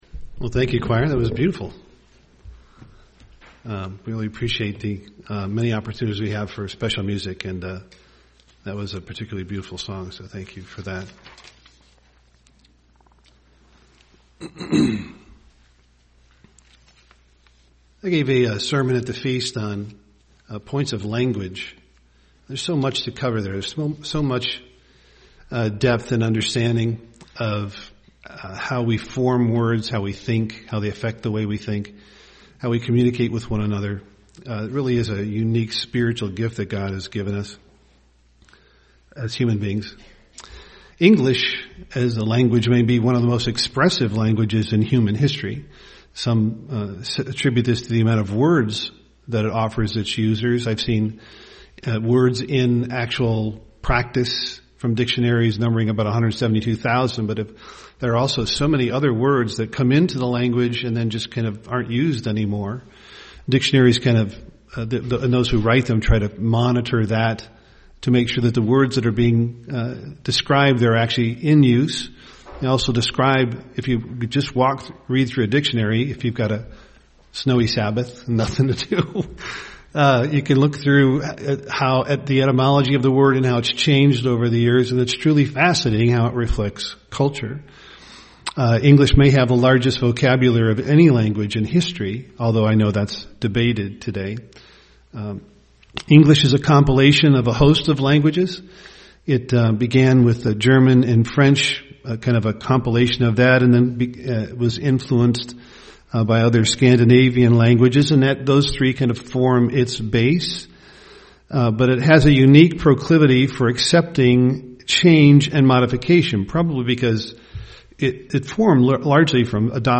UCG Sermon work together Body of Christ Studying the bible?